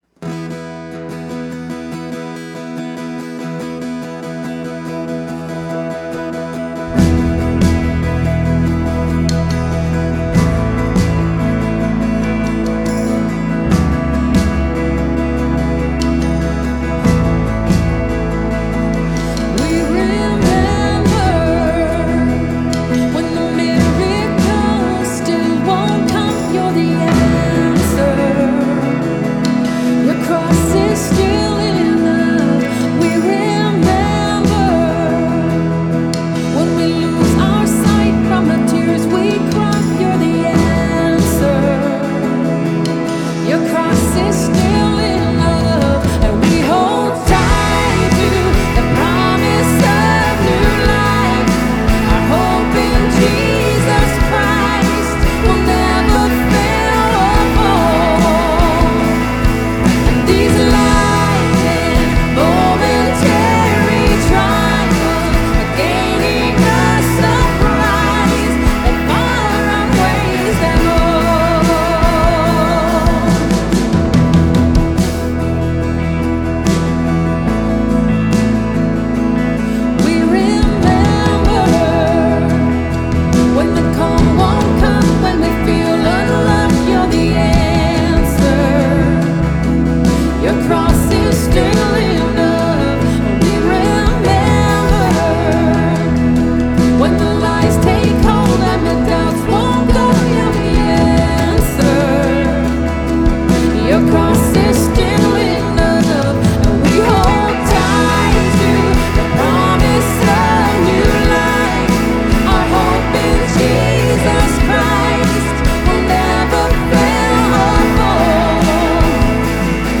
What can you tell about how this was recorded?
Here are some of the musical adventures of the worship team that I've mixed in post-production